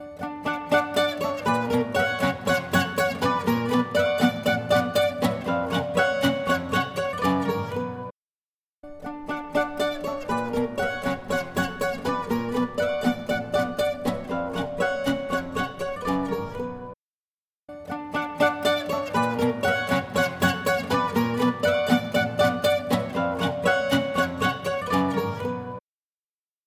EChannel | Acoustic Guitar | Preset: Sublime Acoustic
EChannel-Sublime-Acoustic.mp3